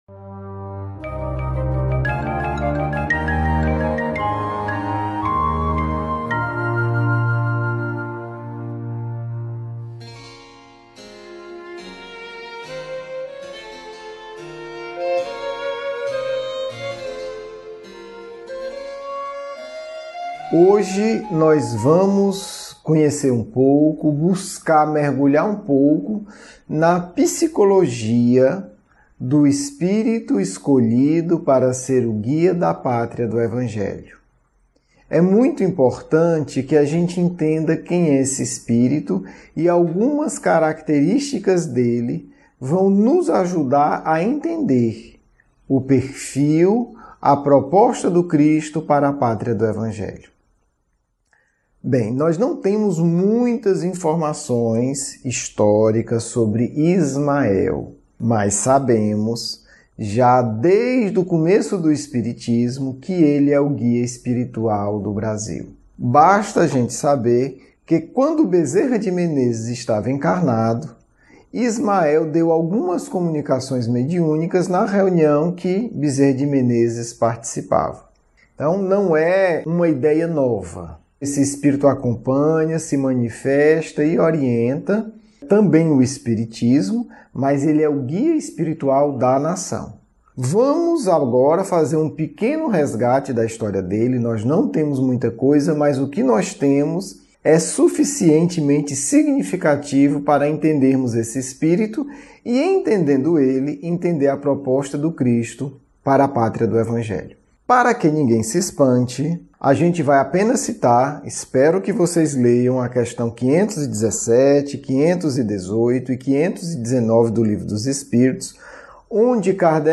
Aula 7 – Anjo Guardião da nação brasileira: Ismael